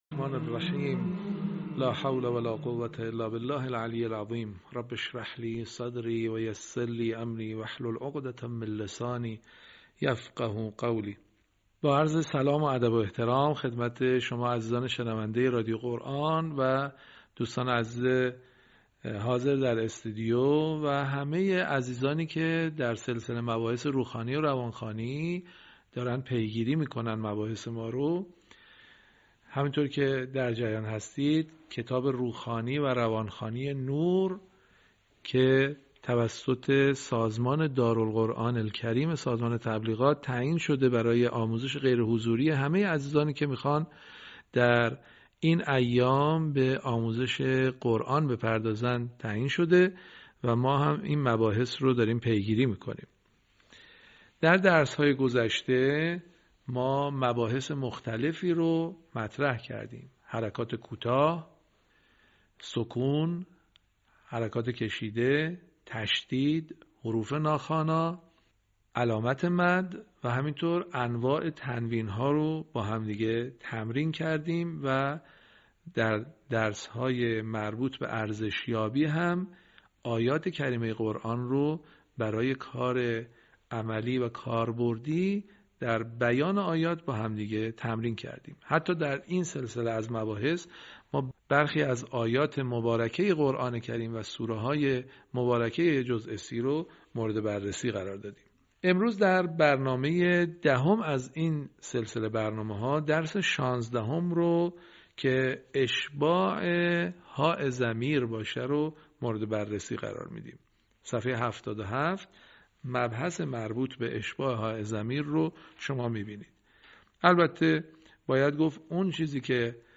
صوت | آموزش «اشباع» در روخوانی و روانخوانی قرآن